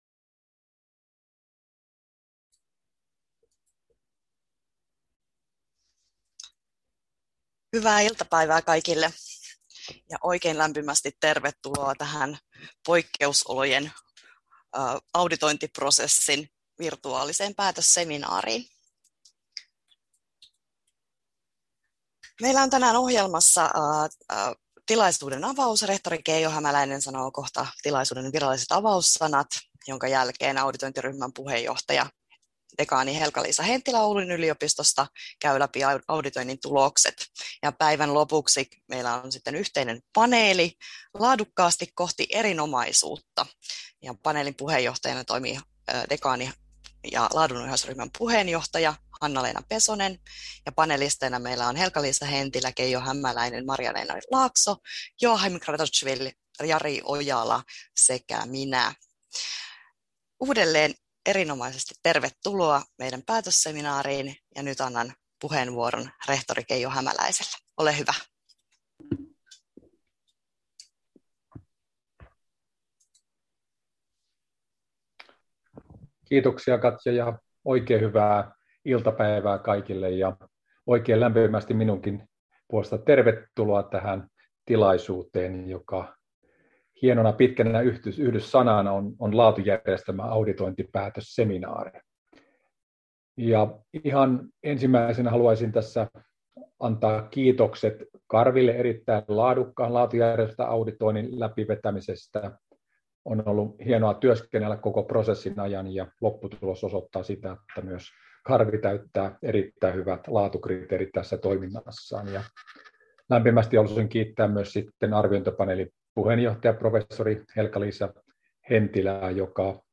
Jyväskylän yliopisto läpäisi Kansallisen koulutuksen arviointikeskuksen (Karvi) toteuttaman auditoinnin hyväksytysti 24.2.2021. Jyväskylän yliopistolle myönnettiin laatuleima, joka on voimassa kuusi vuotta. Auditointiprosessiin kuuluva päätösseminaari järjestettiin torstaina 15.4.2021 klo 14-16.